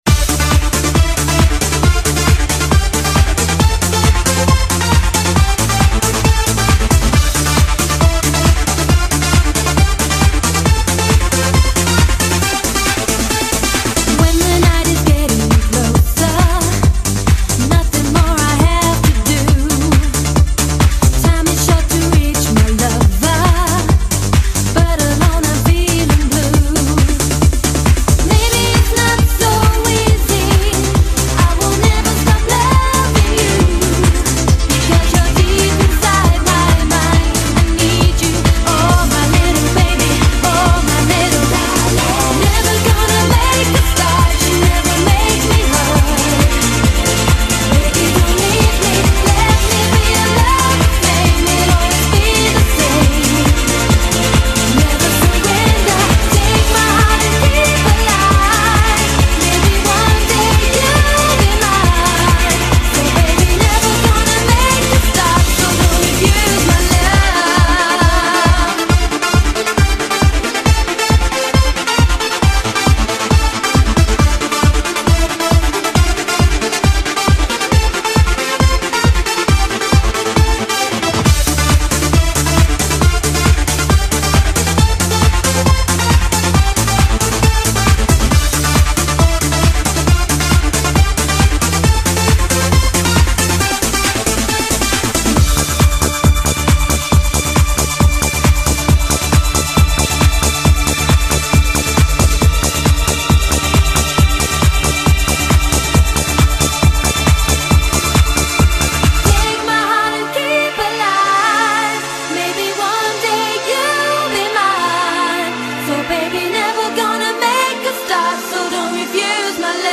BPM136
Audio QualityPerfect (High Quality)